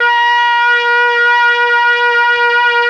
RED.BRASS 28.wav